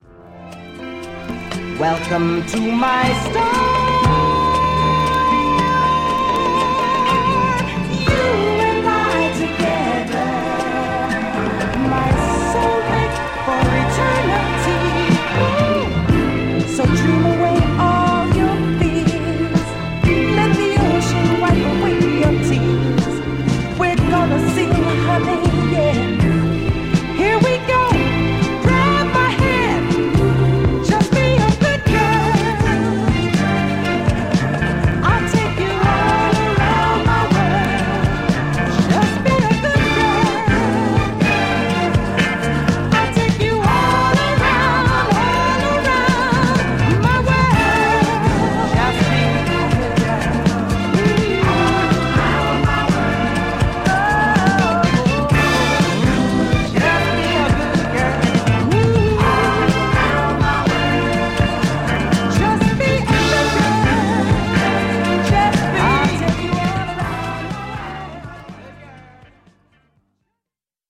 disco/funk/soul
perfect strings and flawless soul